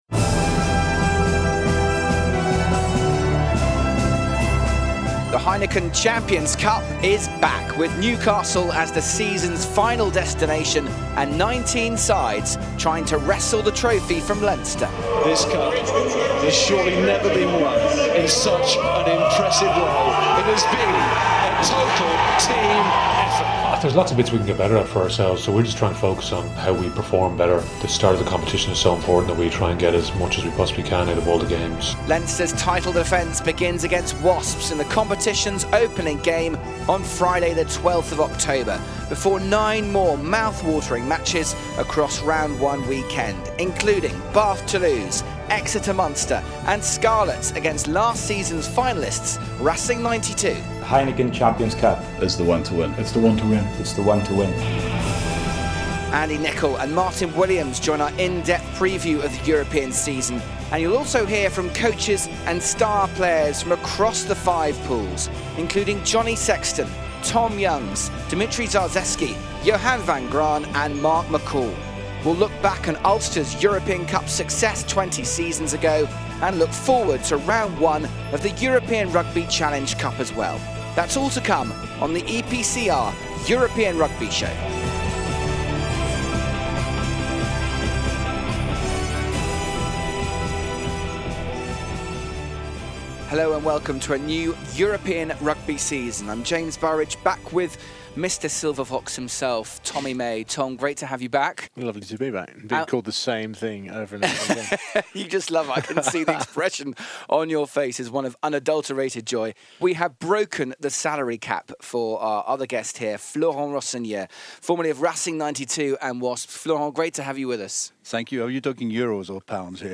Former Wales and Cardiff Blues captain Martyn Williams chats to the EPCR European Rugby Show ahead of a new Heineken Champions Cup campaign, as does former Scotland star Andy Nicol, who skippered Bath Rugby to a European title in 1998.